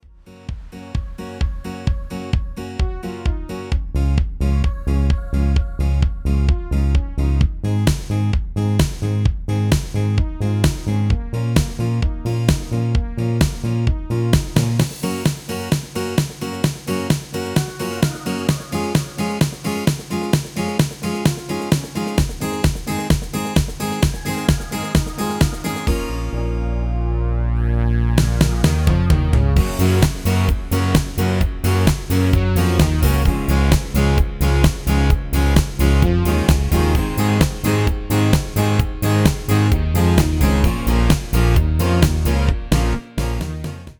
PDF tekstfile - PDF bladmuziek    Vocal Harmony Tracks
Tekst & Akkoorden in keyboard display    Key: Bbm   2:47